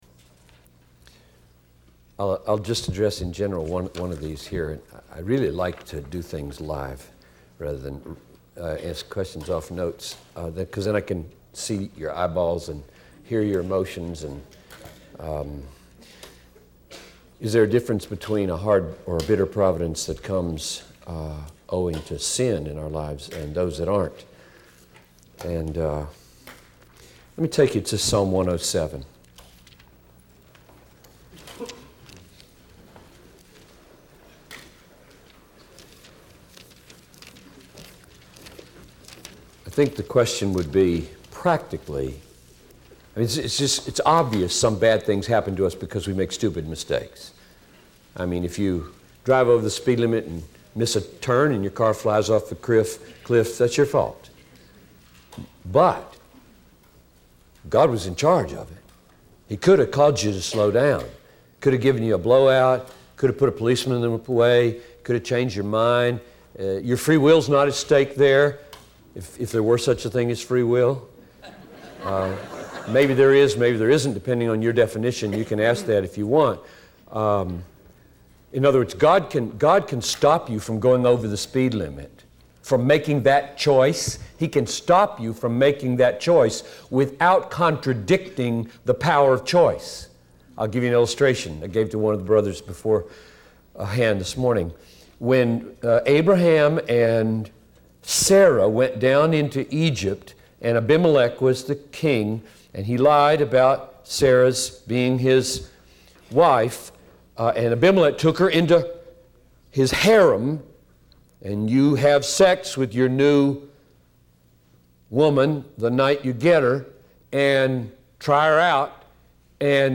Q&A on the Sovereignty of God